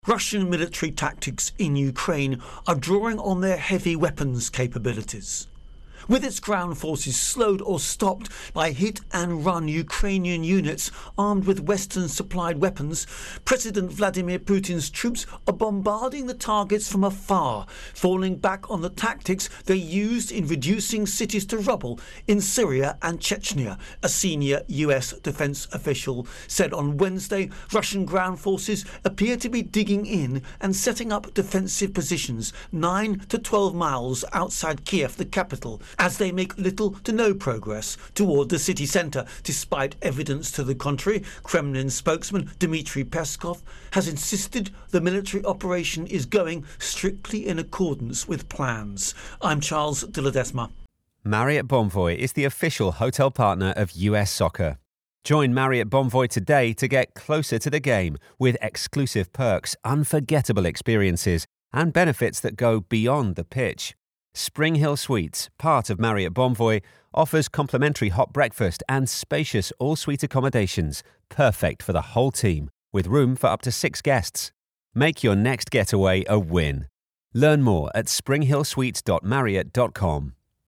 Russia Ukraine War Military Situation Intro and Voicer